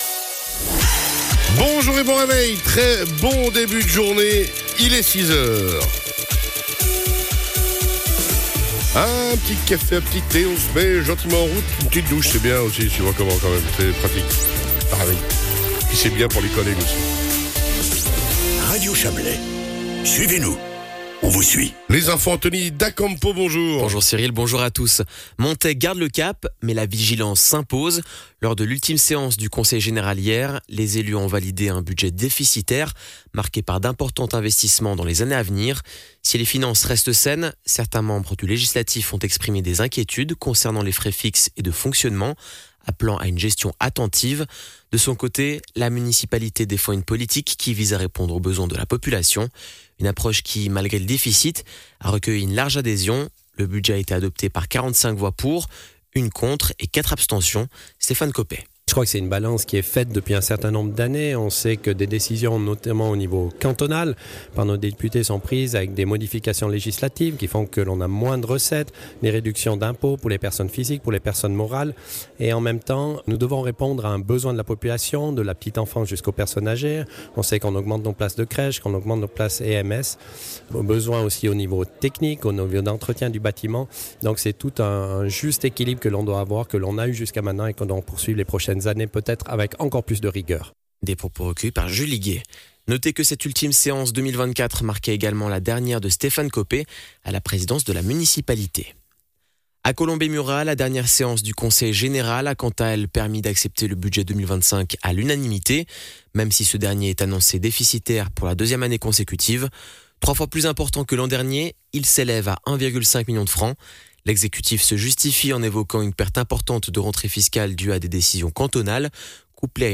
Le journal de 6h00 du 10.12.2024